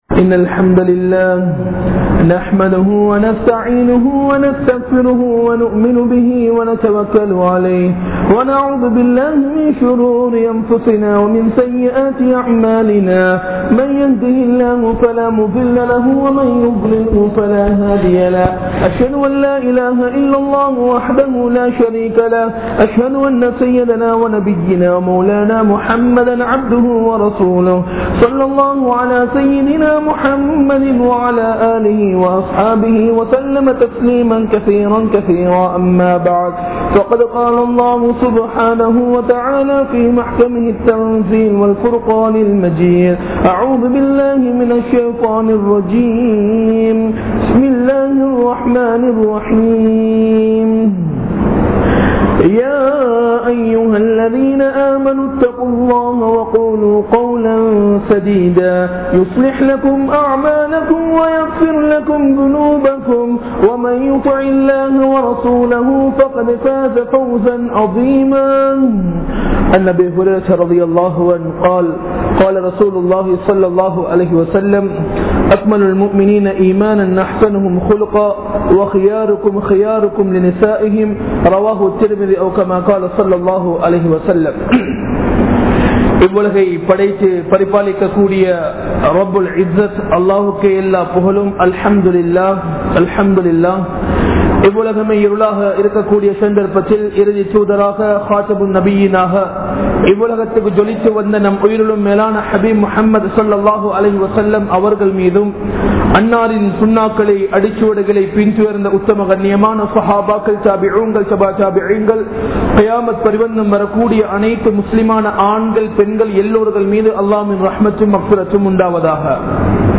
Santhoasamaana Kudumba Vaalkai (சந்தோசமான குடும்ப வாழ்க்கை) | Audio Bayans | All Ceylon Muslim Youth Community | Addalaichenai